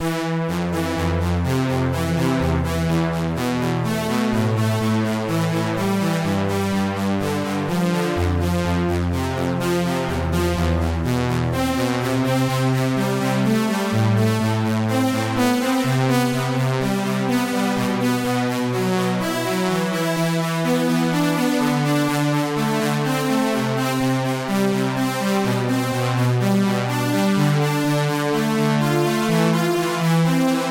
Started writing some background music for Donsol, I'd love to find a sort of middleground between VNV Nation and some kind of chiptune.